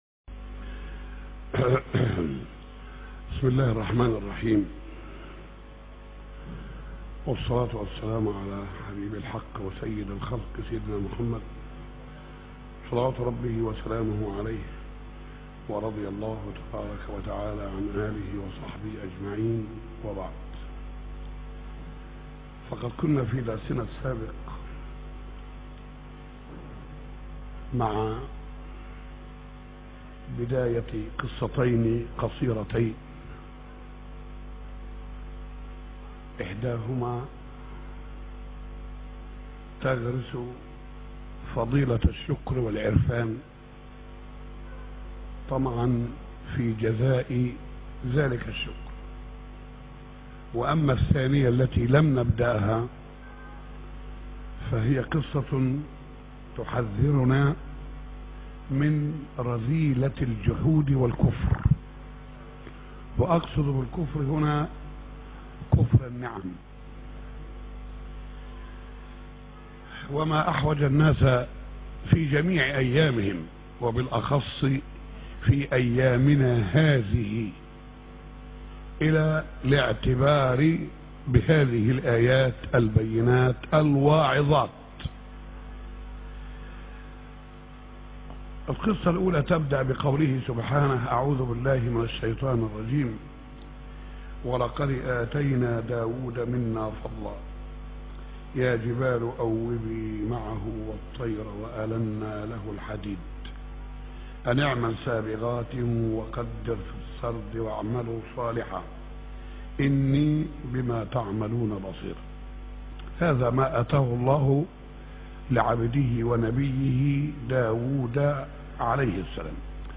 موضوع: سورة سبأ - مسجد ر.شحاته سبأ 1-9